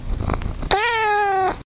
Felis_silvestris_catus.mp3